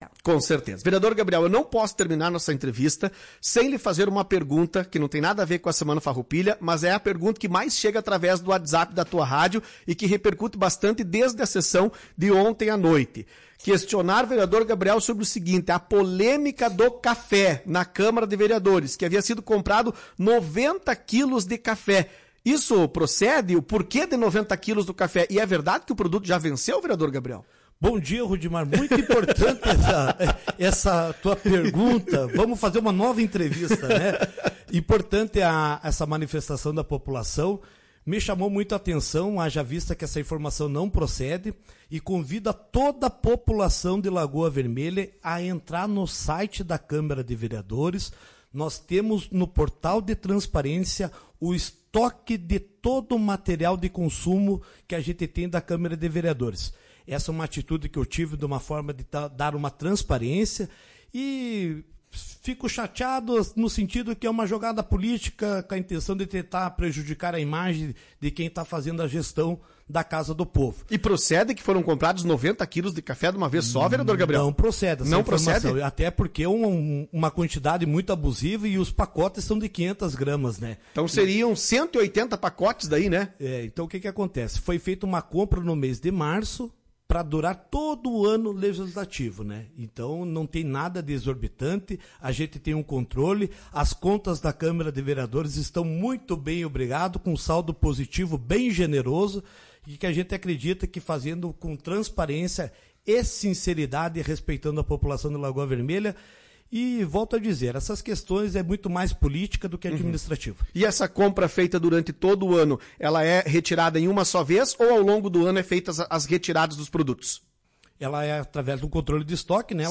Em entrevista à Tua Rádio Cacique, nesta terça, quando questionado se procedia a informação de que o Legislativo realizou a compra 90 kg de pó de café de uma vez só vez, o presidente da Câmara foi enfático: “Não procede.